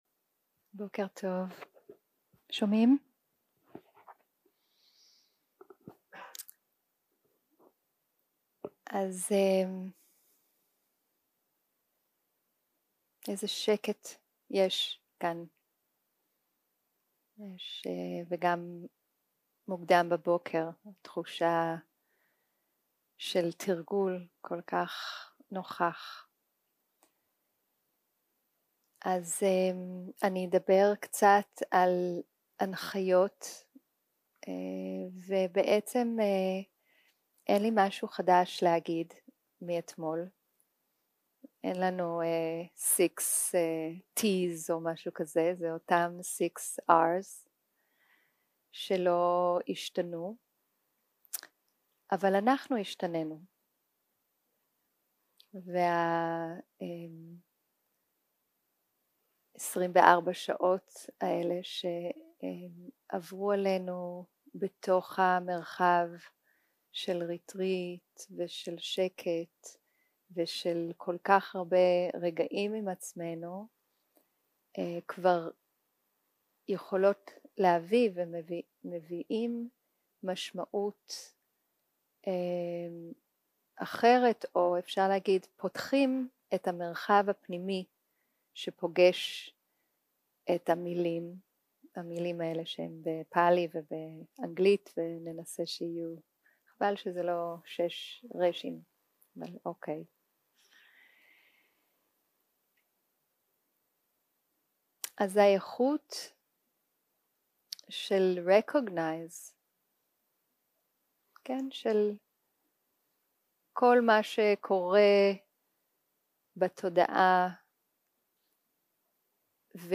יום 3 - הקלטה 5 - בוקר - הנחיות למדיטציה - ששת ה-Rים - המשך 0:00 0:00 סוג ההקלטה: Dharma type: Guided meditation שפת ההקלטה: Dharma talk language: Hebrew